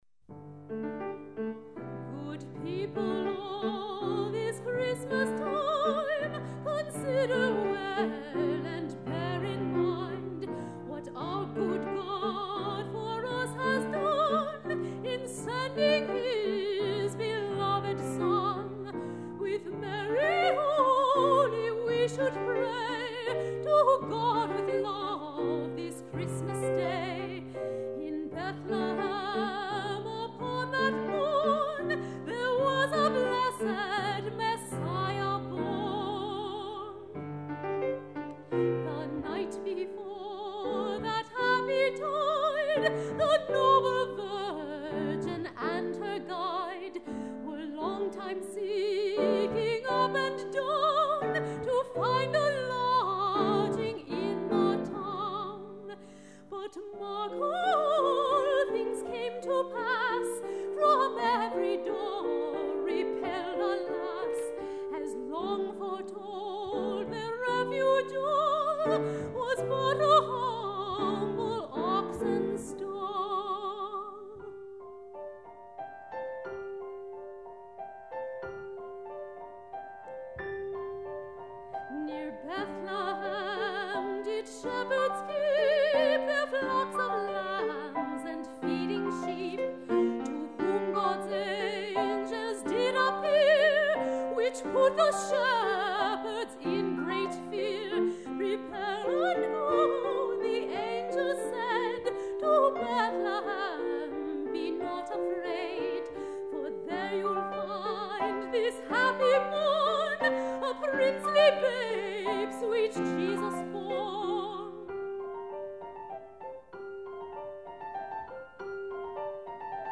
Voix Elevées